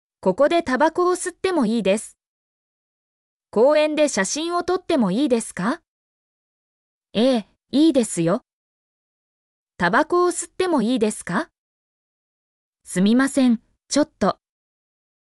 mp3-output-ttsfreedotcom-25_Kf9wsFoa.mp3